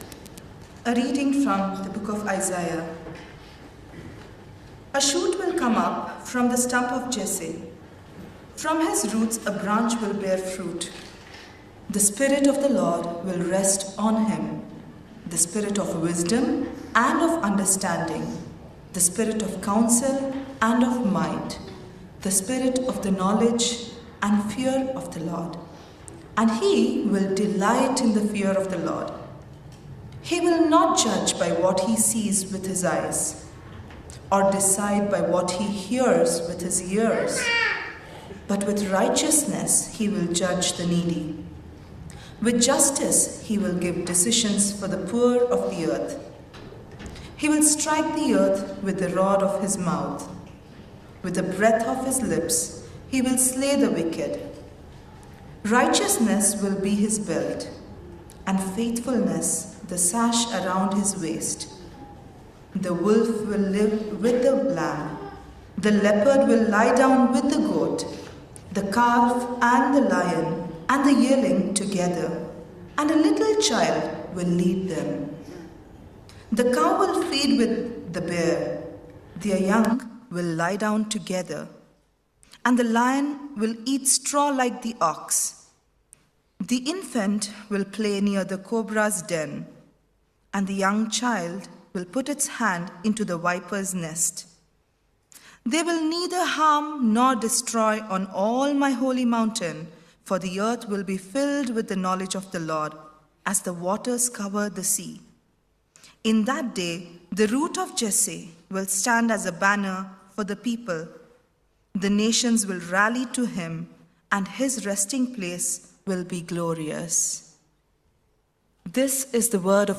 TCE_Sermon-December-7-2025.mp3